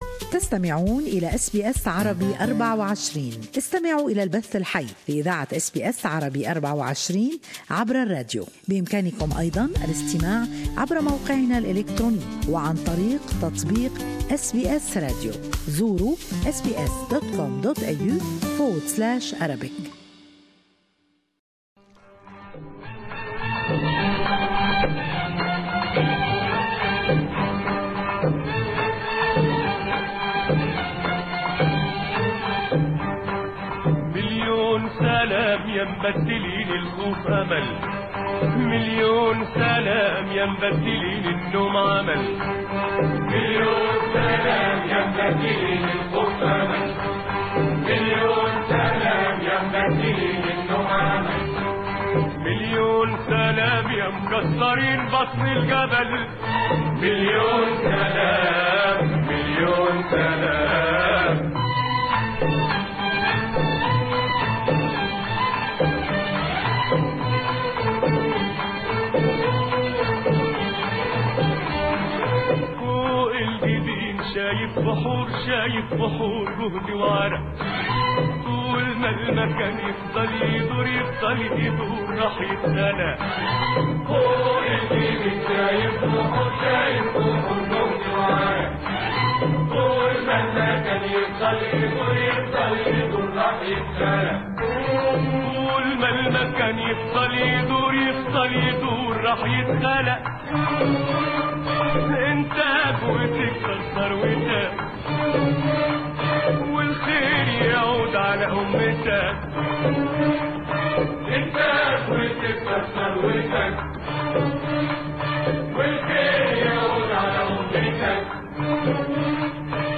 The 1st of May, is the international date for Workers day. In this podcast, you'll listen to a selection of Arabic music that were dedicated to workers in different fields.